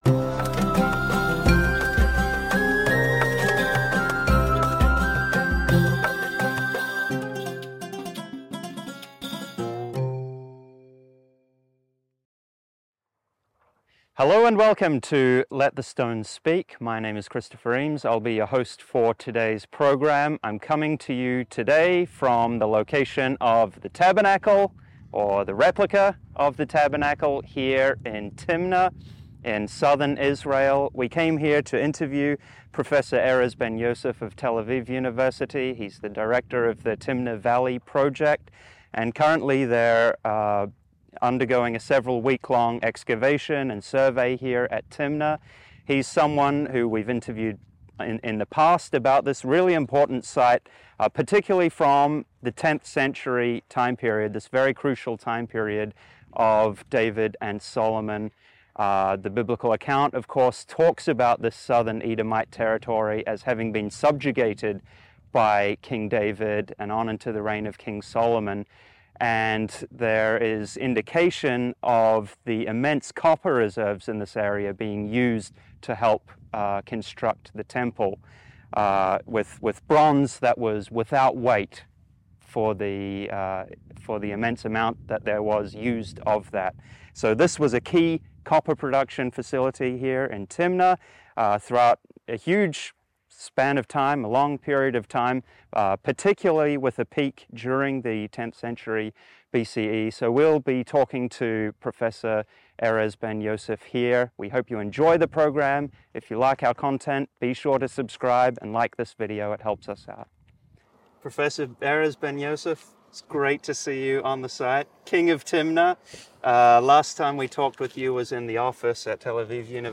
let-the-stones-speak-47-interview-the-copper-mines-of-ancient-edom.mp3